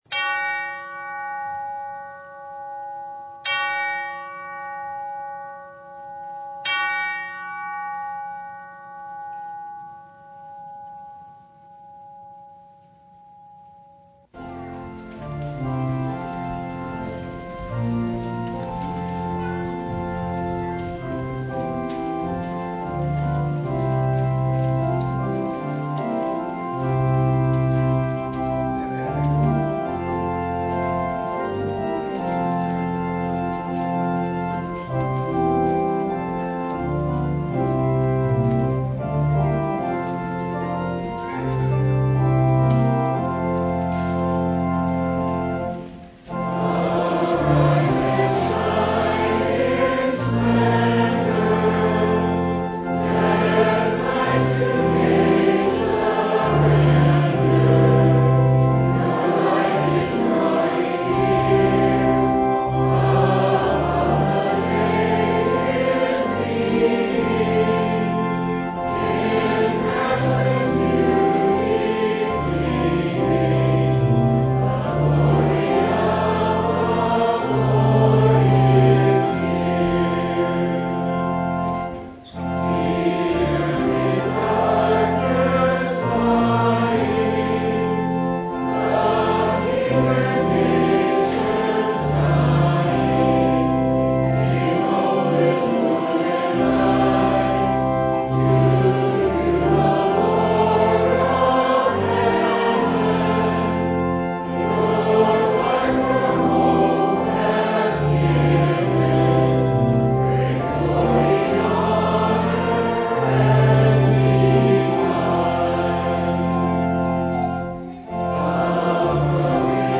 Matthew 5:13-20 Service Type: Sunday Jesus hasn’t come to abolish or relax the law and the prophets.